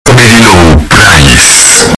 Earrape Soundboard
Very Low Price earrape
very-low-price-earrape.mp3